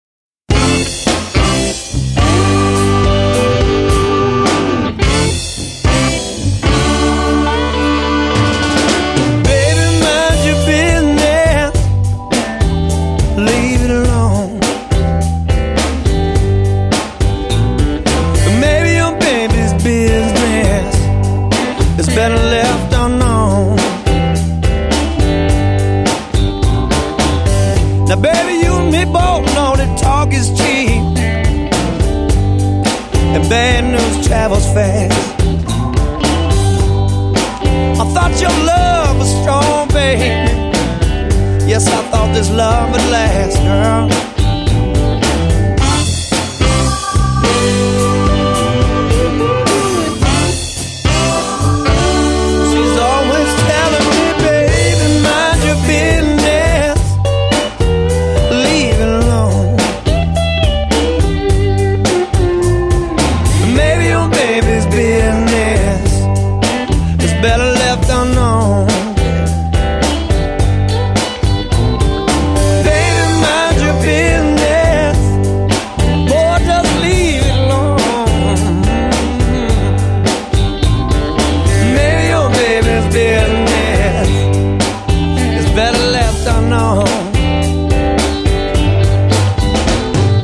完全德國製造雙碟裝黑膠版
「音準對比」系列 (Reference Sound Edition)